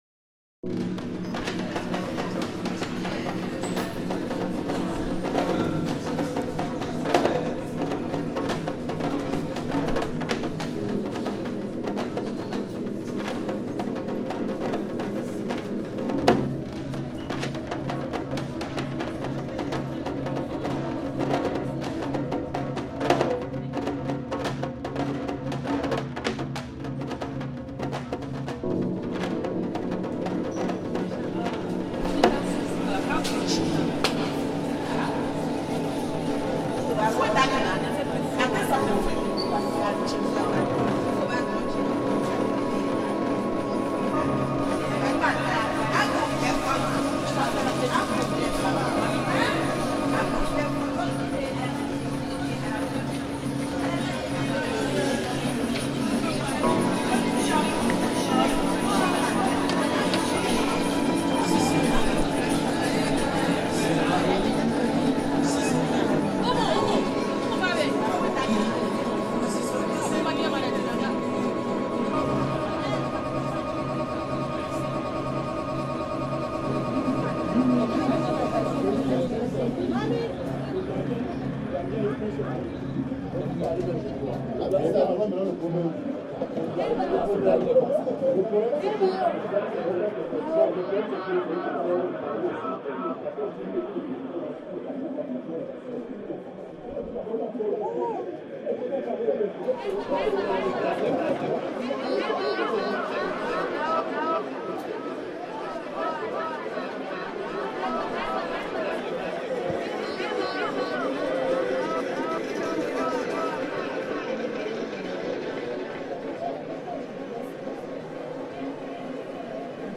I took an approach that incorporates various languages and dialects encountered during migration into the composition.
Rabat market reimagined